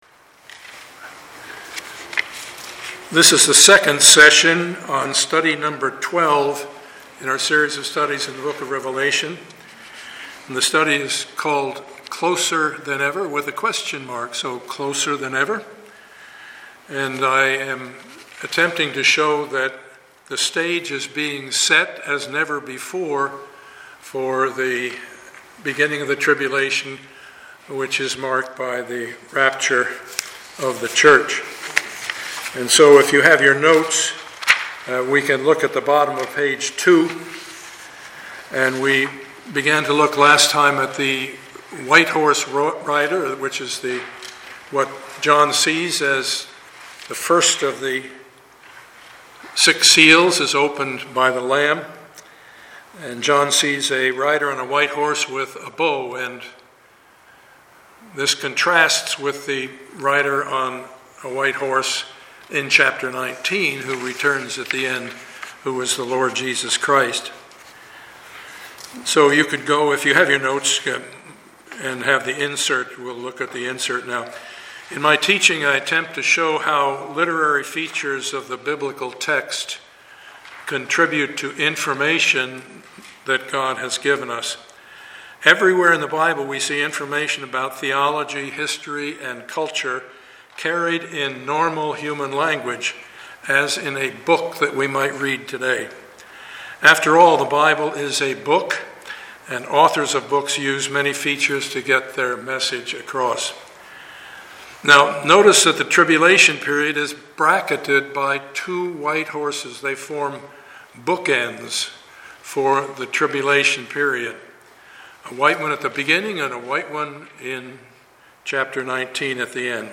Passage: Revelation 6:1-17 Service Type: Sunday morning